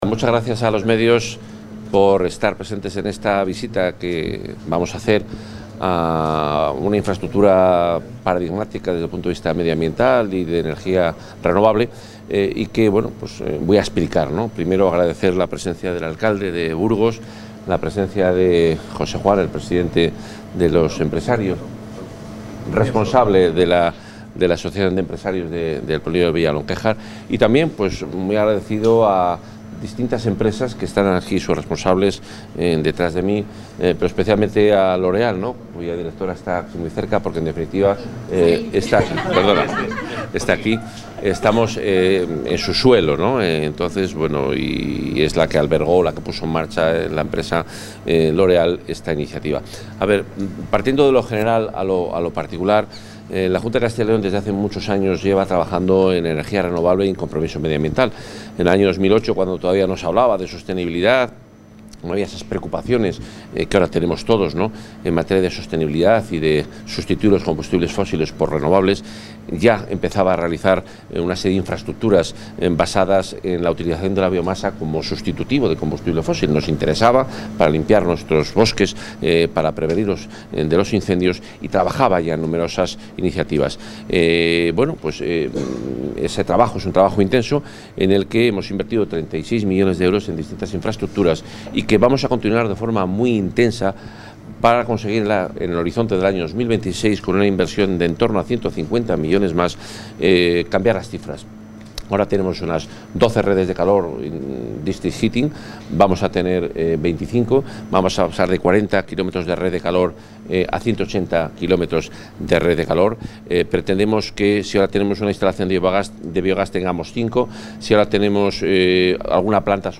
Intervención del consejero.
El consejero de Medio Ambiente, Vivienda y Ordenación del Territorio, Juan Carlos Suárez-Quiñones, ha visitado esta mañana el estado de las obras de la segunda fase de la Red de Calor Sostenible del polígono industrial de Villalonquéjar, en Burgos, gestionada por la Junta, a través de Somacyl, que dará servicio a nuevas industrias.